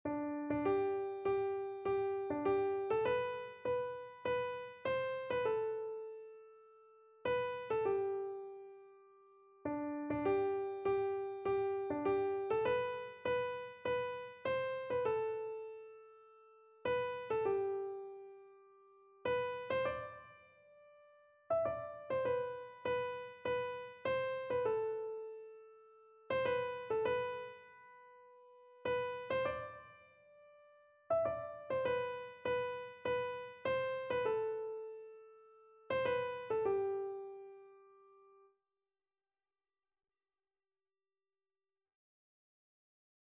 Christian
Free Sheet music for Keyboard (Melody and Chords)
4/4 (View more 4/4 Music)
Keyboard  (View more Intermediate Keyboard Music)
Classical (View more Classical Keyboard Music)